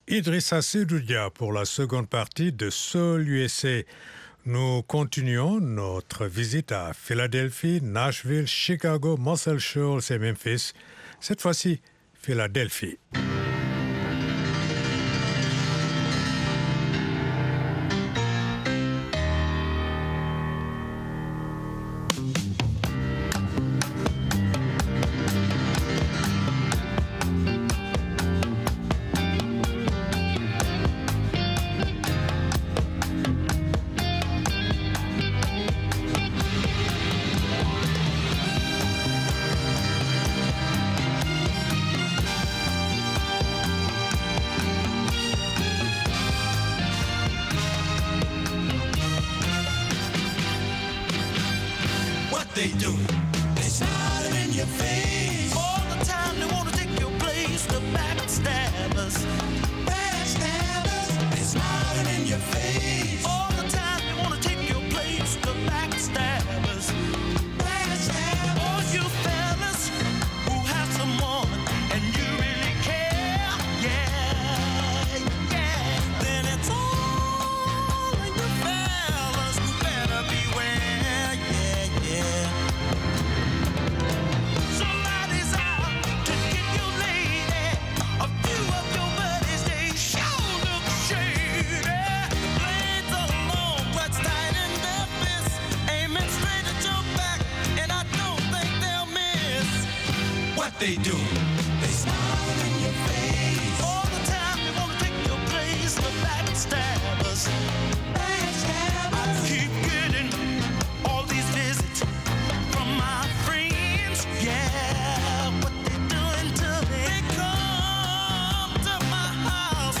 Soul USA - un retour dans les endroits mythiques de la Soul des années 60 et 70.